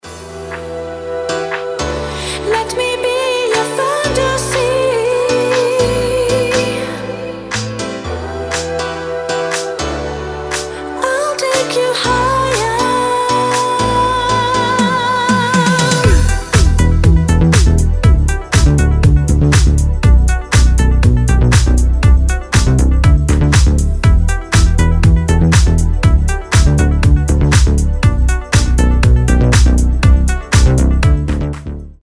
• Качество: 320, Stereo
чувственные
Стиль: deep house